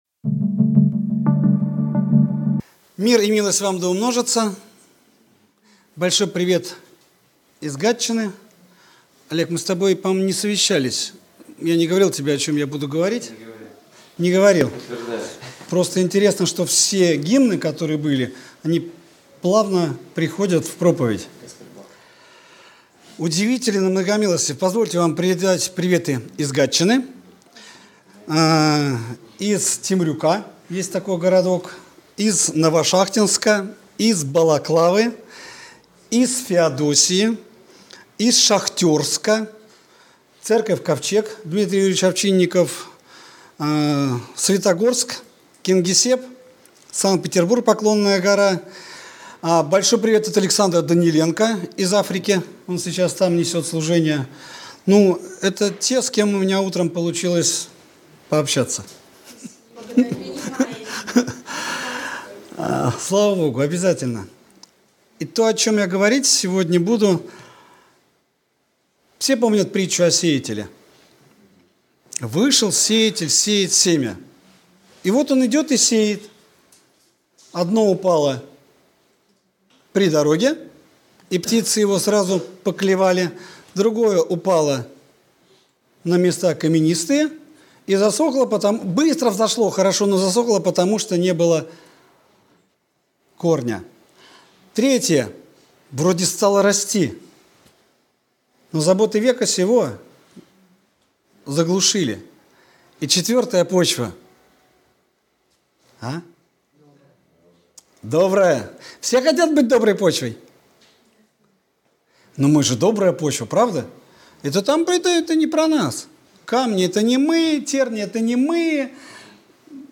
Воскресная проповедь - 2024-12-01 - Сайт церкви Преображение